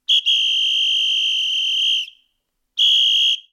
おもちゃの汽笛